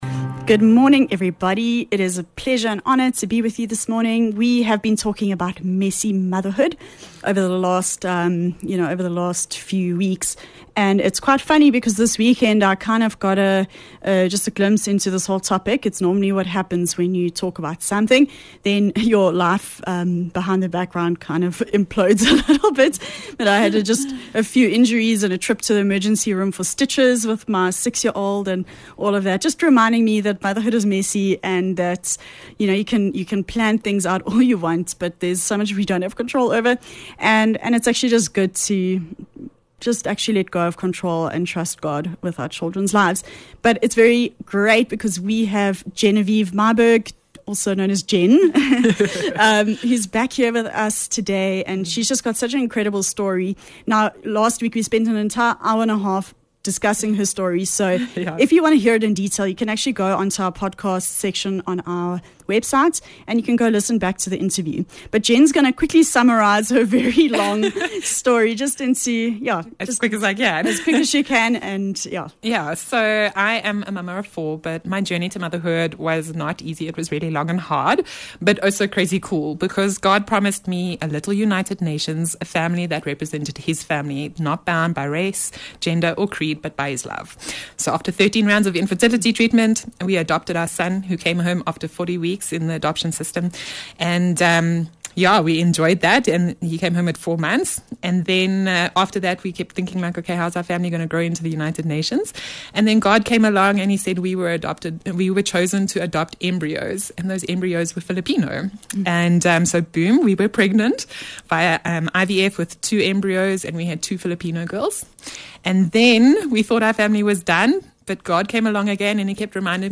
22 Mar Messy Motherhood - Interview